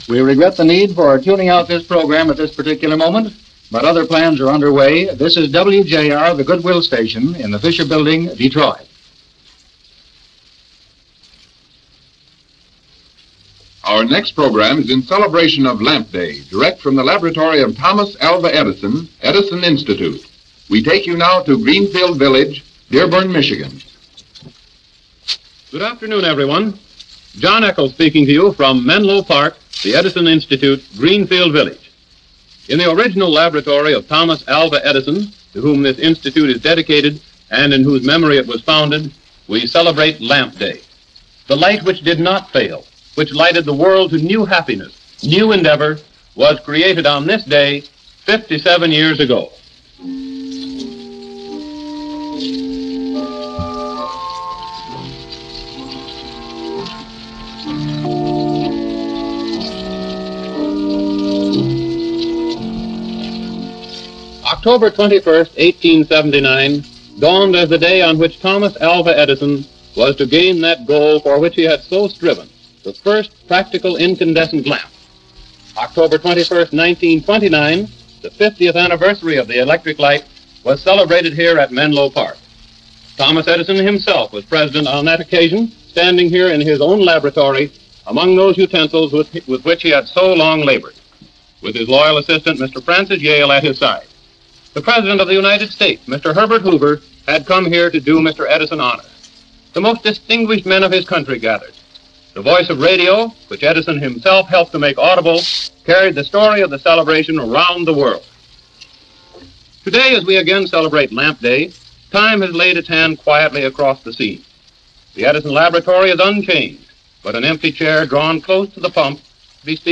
Thomas Edison Memorial Lamp Day - October 21, 1936 as it was broadcast live over WJR-Detroit, 83 years ago.